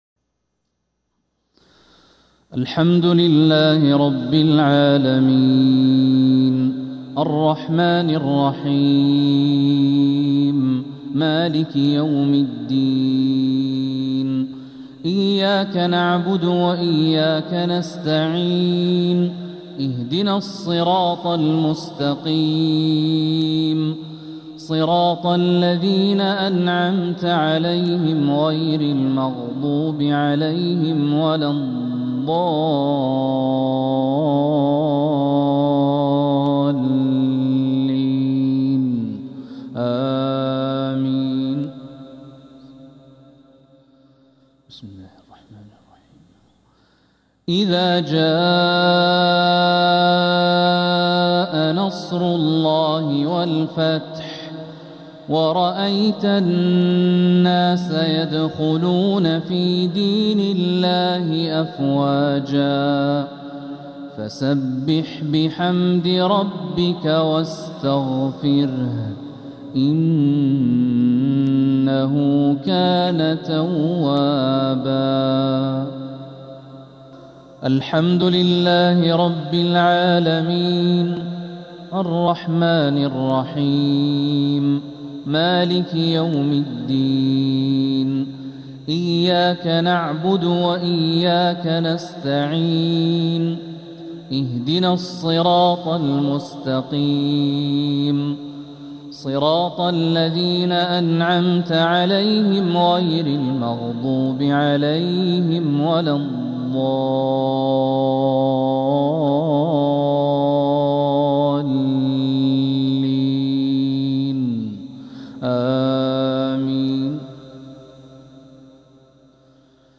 مغرب الخميس 8 محرم 1447هـ سورتي النصر و المسد كاملة | Maghrib prayer from Surah An-Nasr and Al-Masad 3-7-2025 > 1447 🕋 > الفروض - تلاوات الحرمين